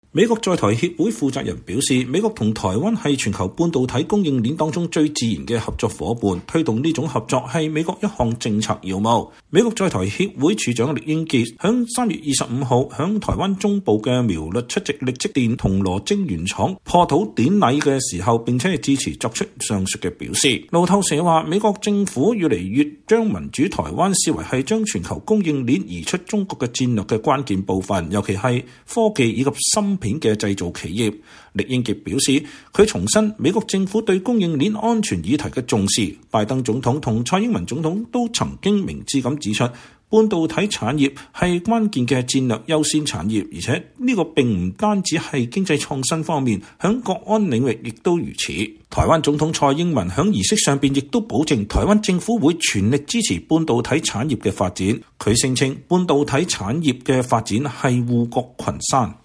美國在台協會處長酈英傑（Brent Christensen）在台灣出席力積電銅鑼晶圓廠破土典禮並致辭。（2021年3月25日）